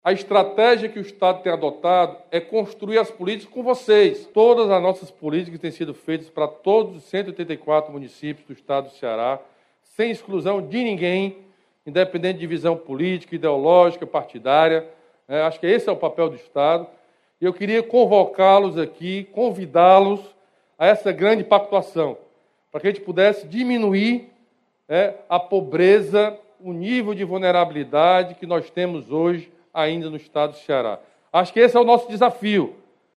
A solenidade de lançamento, que aconteceu no Palácio da Abolição, em Fortaleza, serviu como um dos eventos-teste do Governo do Ceará na retomada gradual dos eventos por causa da pandemia do Coronavírus.
O governador Camilo Santana destacou que, assim como o Estado fez em outras áreas, a ideia é criar critérios e ações compartilhados com os municípios para conquistar avanços sociais.